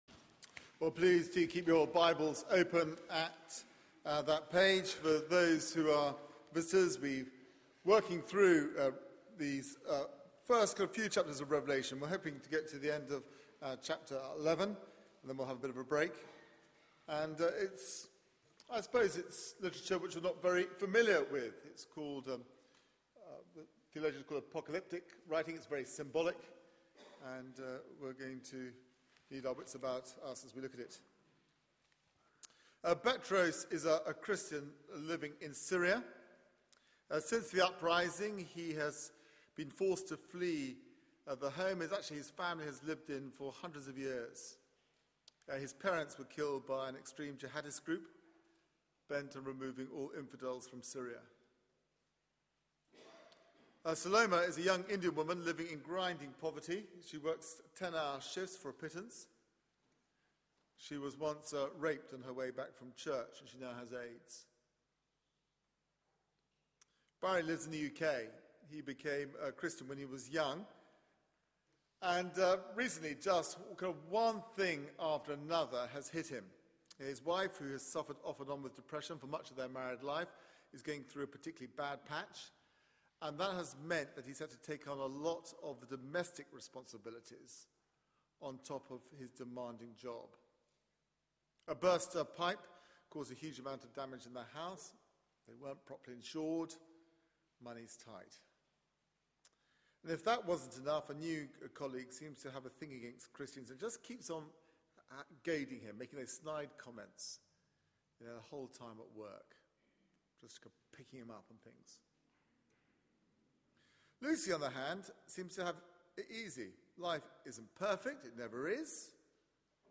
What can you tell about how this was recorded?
Media for 9:15am Service on Sun 21st Apr 2013 09:15 Speaker